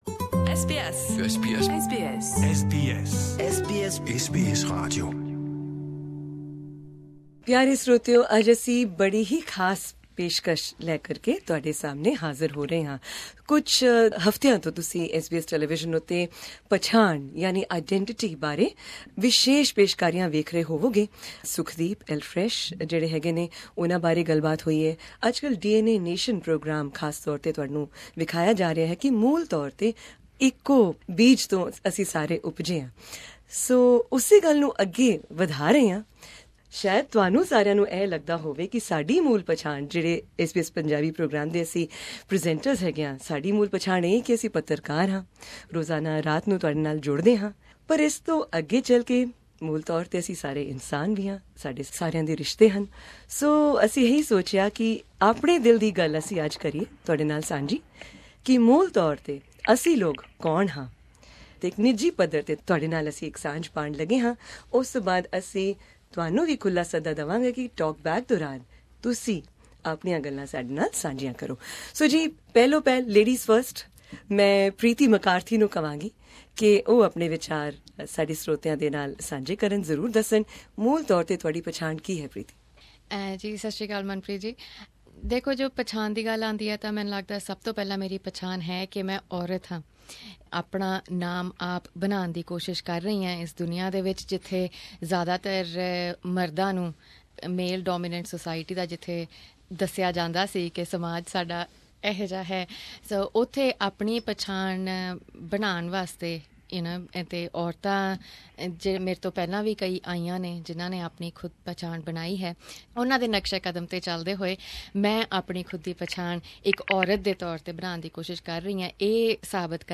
Here we have SBS Punjabi team members in a panel discussion trying to explain what the word identity means to them.